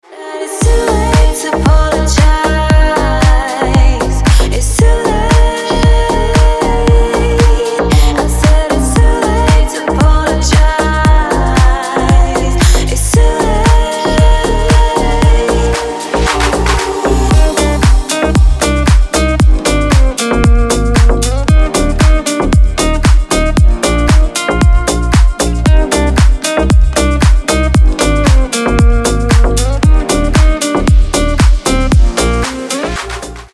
• Качество: 320, Stereo
ритмичные
Cover
красивый женский голос
ремиксы
Стиль: house